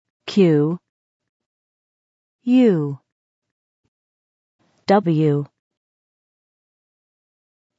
Note: il s'agit ici des prononciations anglaises.